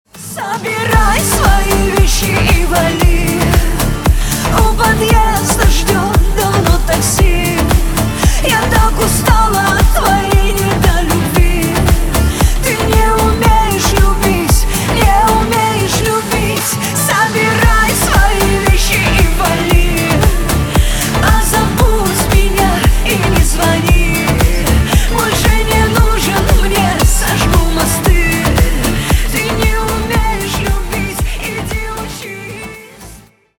Поп Музыка # кавказские